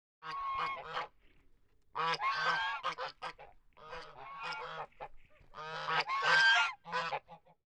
Index of /90_sSampleCDs/E-MU Producer Series Vol. 3 – Hollywood Sound Effects/Human & Animal/Geese
GEESE 2-L.wav